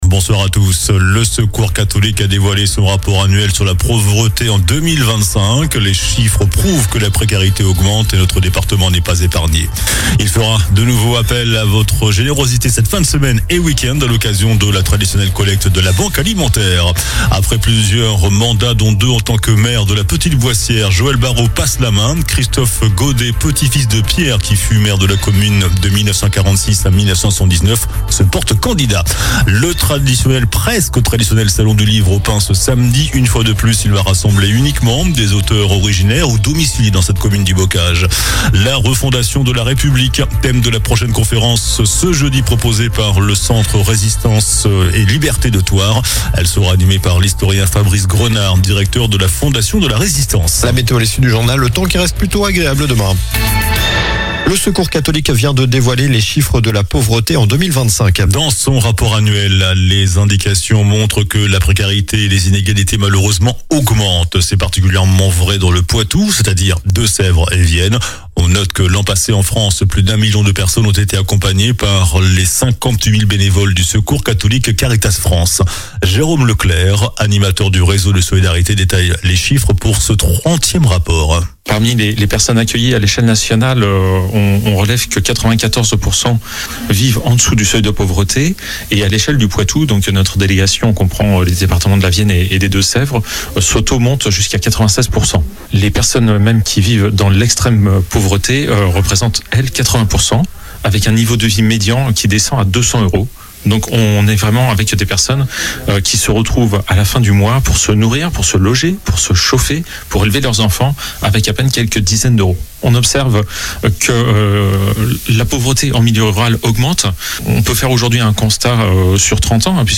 JOURNAL DU MARDI 25 NOVEMBRE ( SOIR )